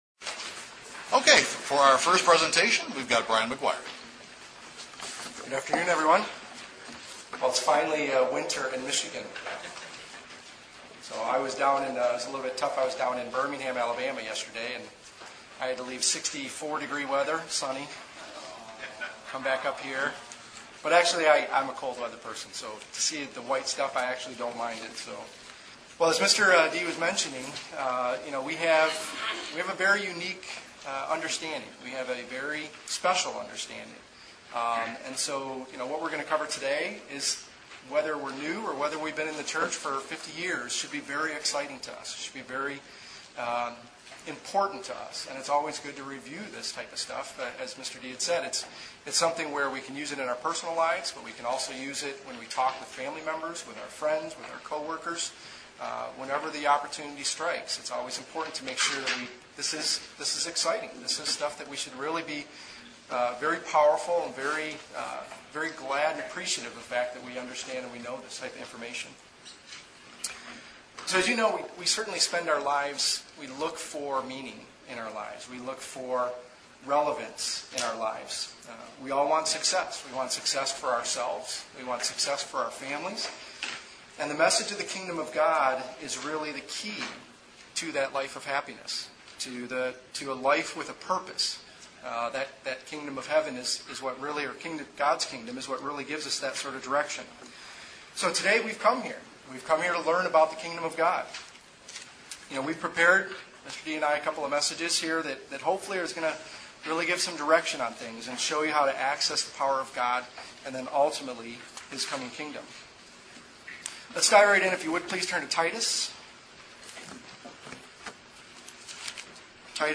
Given in Detroit, MI
UCG Sermon Studying the bible?